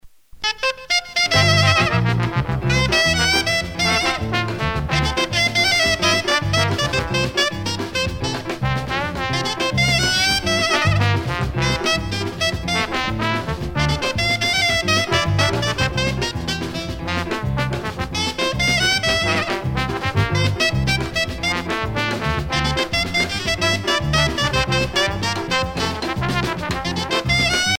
danse : biguine
Pièce musicale éditée